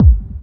Index of /90_sSampleCDs/Club_Techno/Percussion/Kick
Kick_22.wav